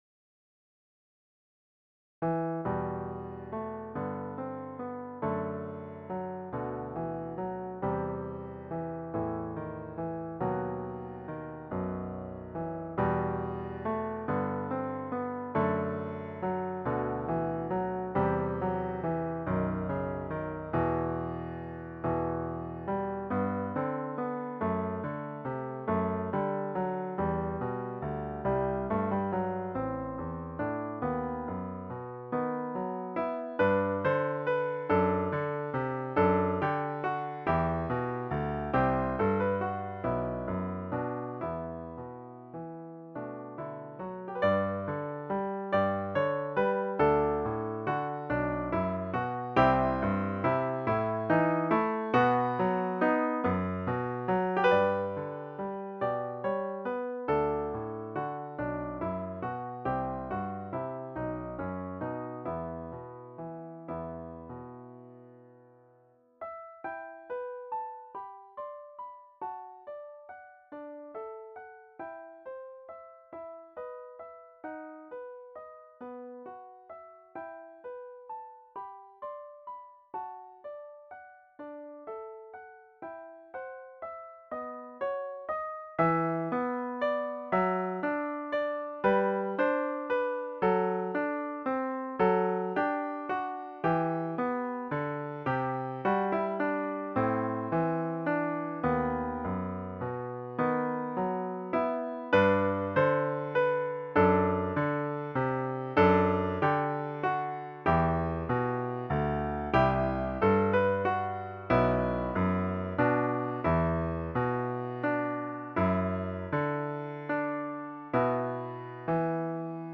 Christmas Piano Book – intermediate to late intermediate